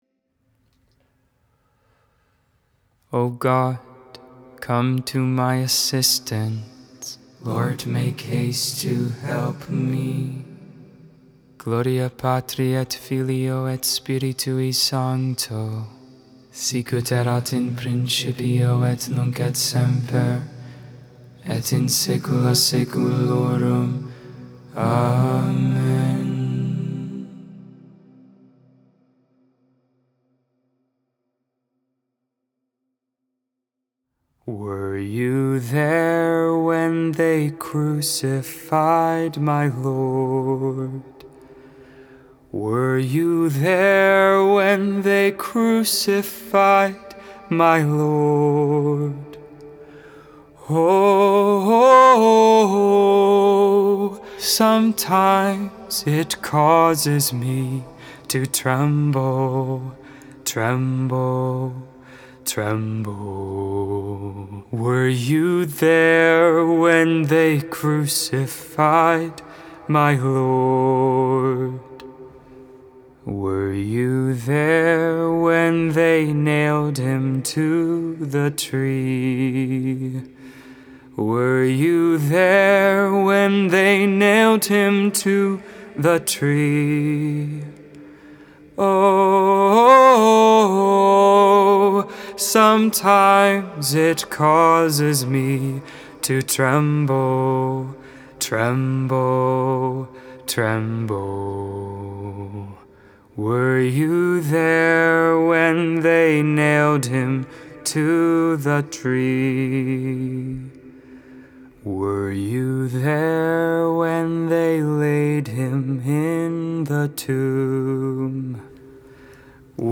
Vespers, Evening Prayer for Holy Saturday, April 16th, 2022.
Hymn: Were You There When They Crucified My Lord? Psalm 116v10-19 Psalm 143v1-11 Canticle: Philippians 2v6-11 Reading: 11 Peter 1v18-21 Magnificat: Luke 1v46-55 (English, tone 8) Intercessions: Lord Have Mercy On Us.